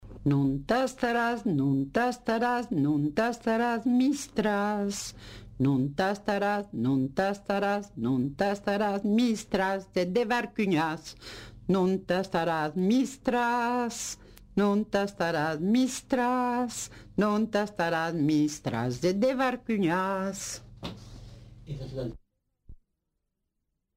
Lieu : Montauban-de-Luchon
Genre : chant
Effectif : 1
Type de voix : voix de femme
Production du son : chanté
Danse : tres pases